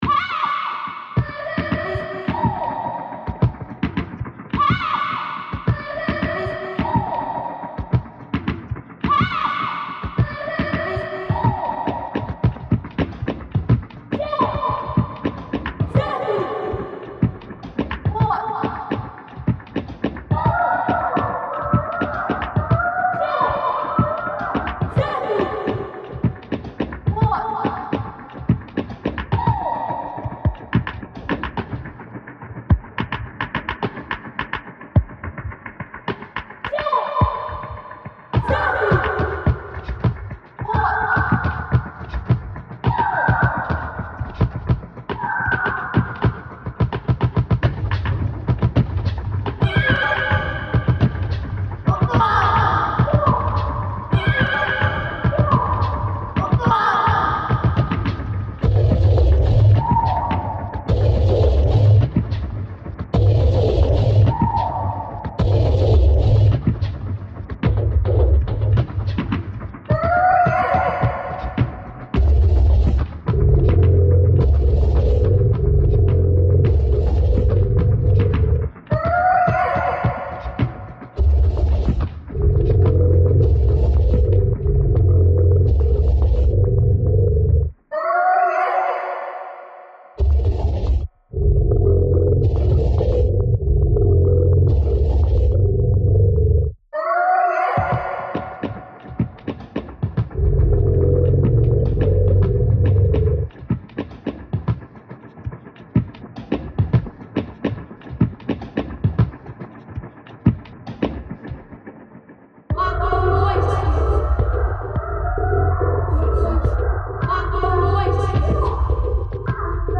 All the elements to make a remix are all contained in the original field recording.
Lamma Island playground reimagined